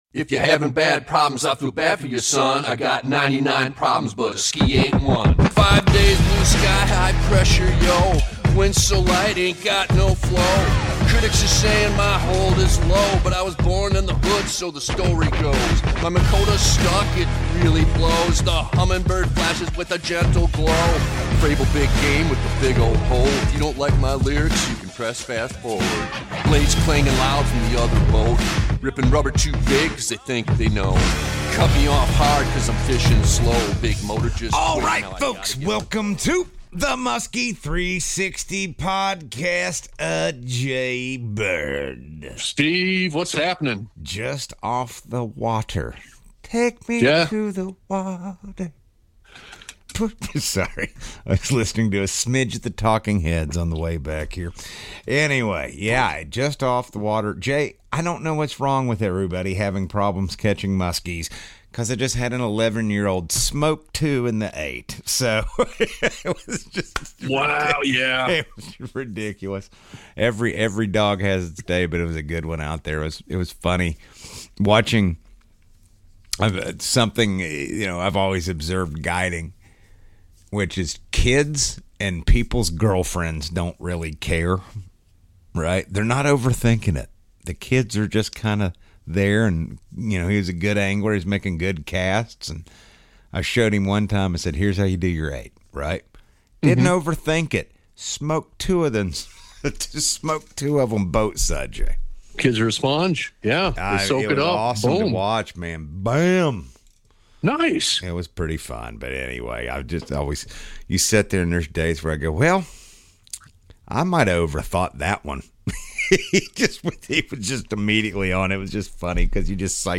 one on one conversation packed with unconventional fishing insight. Considered as the greatest angler of all time, Clunn breaks down crossover fishing tactics, pattern recognition, and the mindset that consistently puts him ahead of the field.